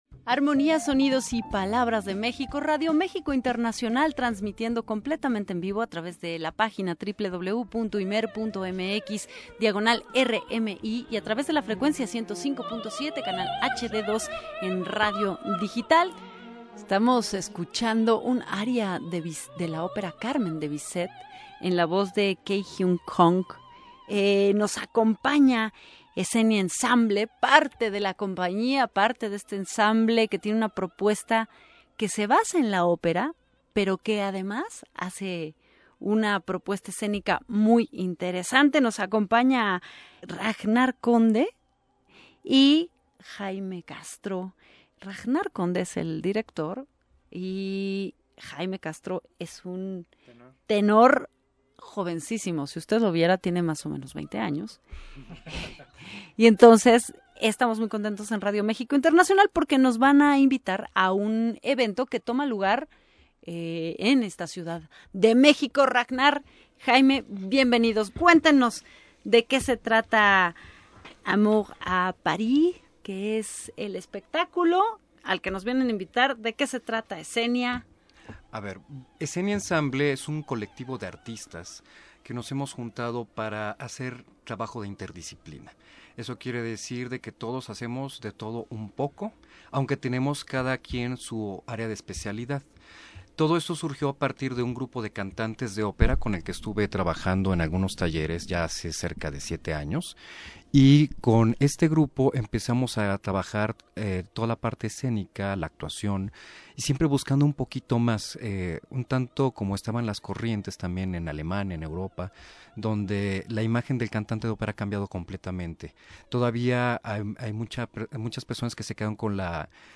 entrevista_escenia.mp3